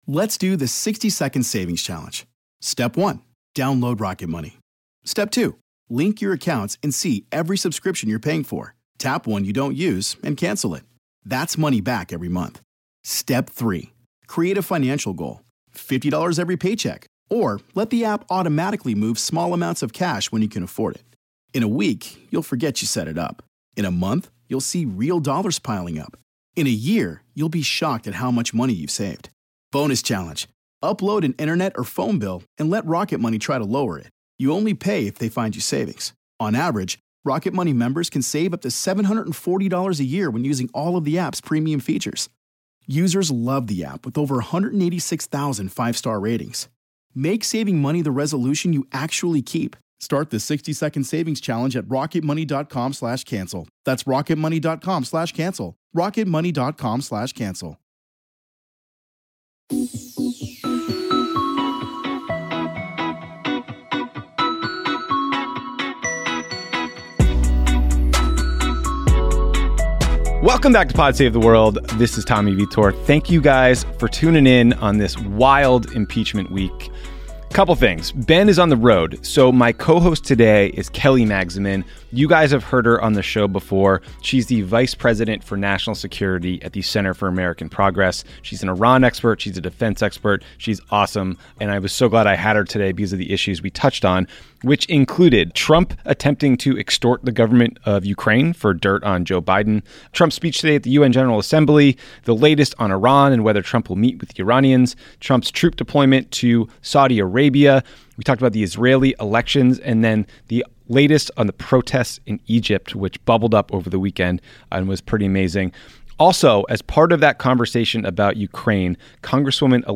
Later, Tommy is joined by Congresswoman Elissa Slotkin, a former CIA officer who joined six of her colleagues with national security backgrounds to say the allegations against Trump on Ukraine would be an impeachable offense. Then, Tommy speaks with former US Ambassador to the United Nations Samantha Power about Syria, national security decision making and her new book, The Education of an